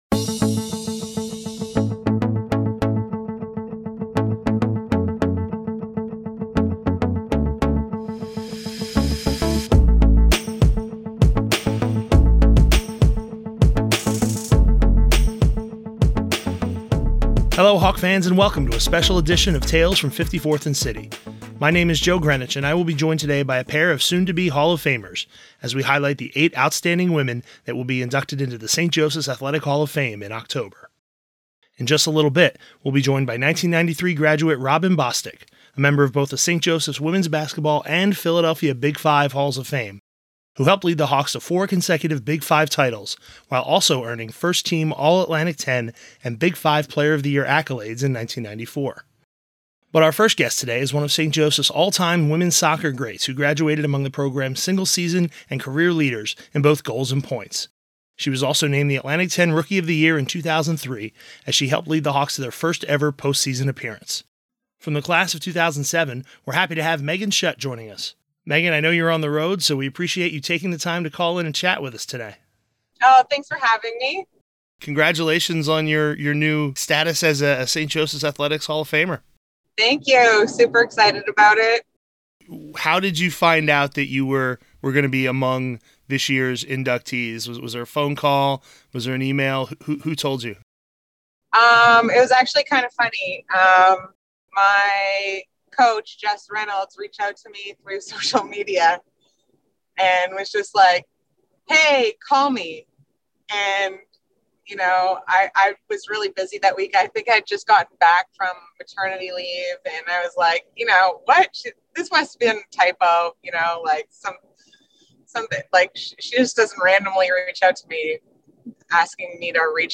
September 28, 2022 On a special edition of the Tales from 54th and City, two soon-to-be Saint Joseph's Athletic Hall of Famers talk about their time on Hawk Hill.